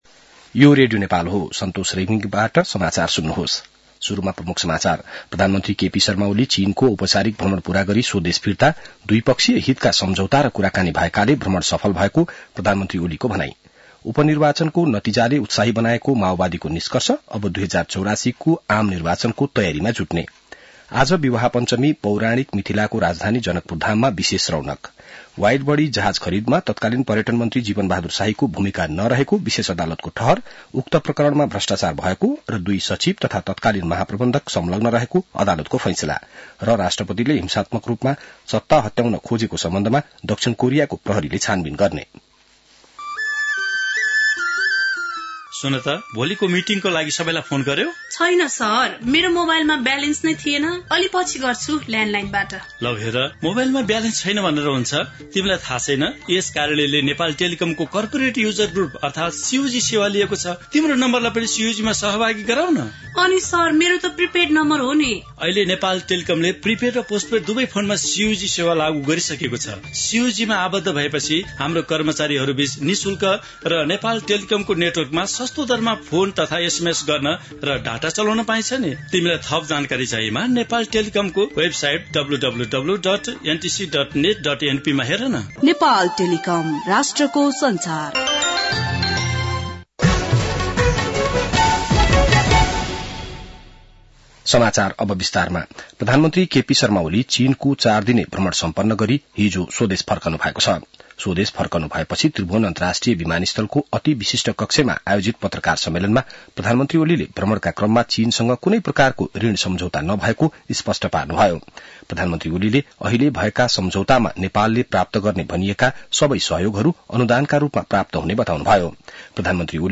बिहान ७ बजेको नेपाली समाचार : २२ मंसिर , २०८१